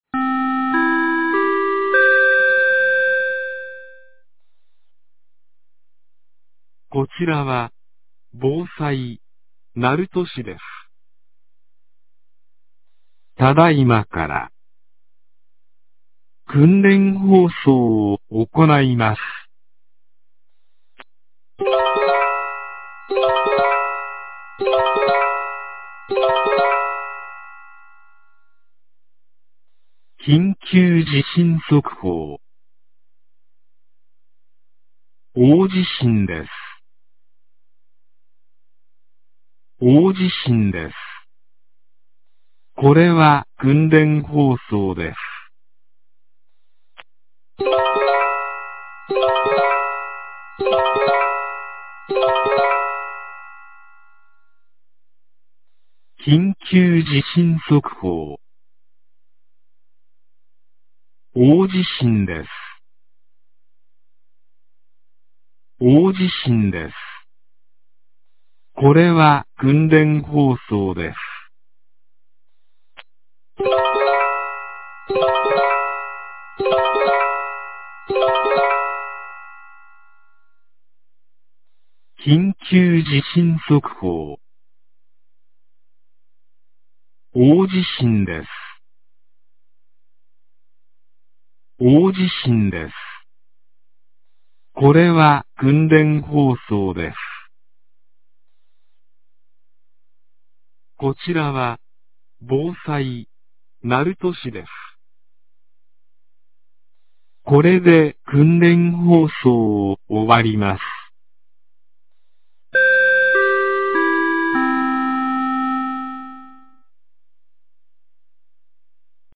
2025年11月05日 10時02分に、鳴門市より全地区へ放送がありました。